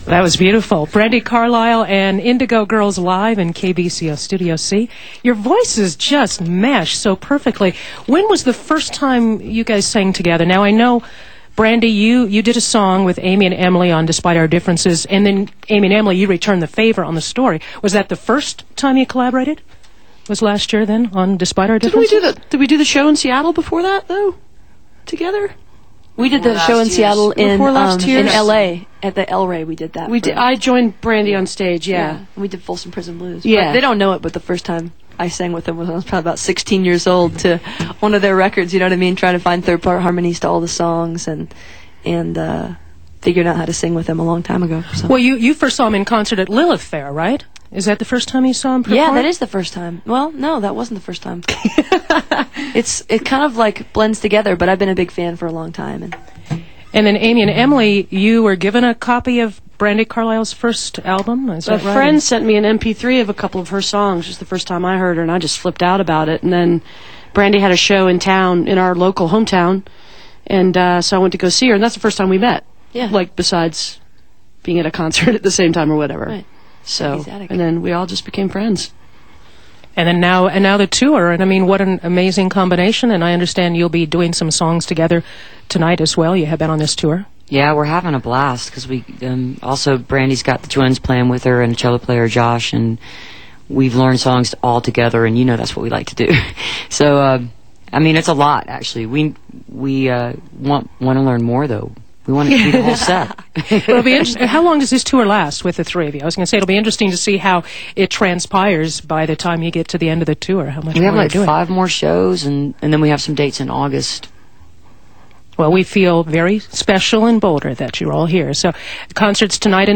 lifeblood: bootlegs: 2007-06-20: kbco - boulder, colorado (with brandi carlile)
03. interview (2:19)